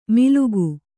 ♪ milugu